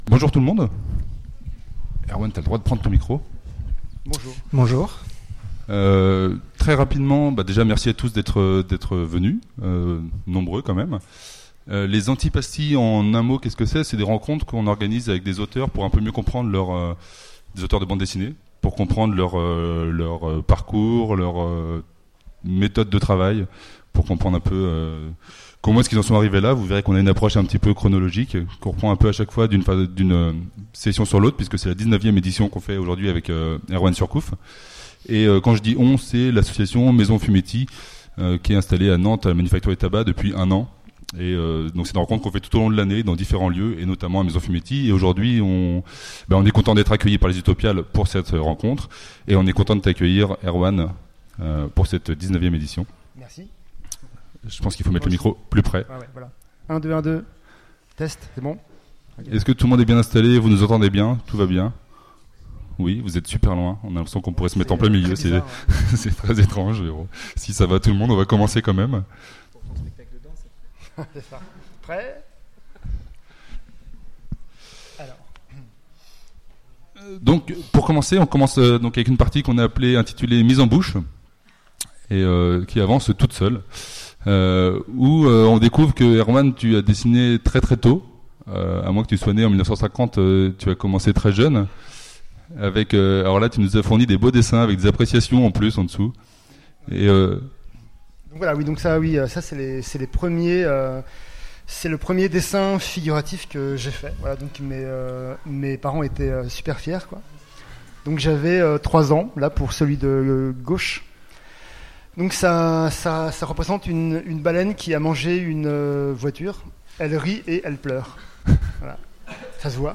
Utopiales 2017 : Conférence Antipasti
Mots-clés Rencontre avec un auteur Conference Partager cet article